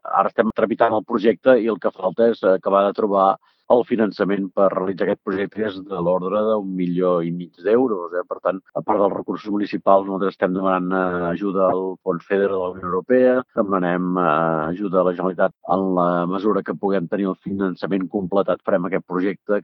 El batlle però defensa que fins que el finançament no estigui solucionat no es podrà començar a fer el projecte. Josep Maria Rufí a Ràdio Capital.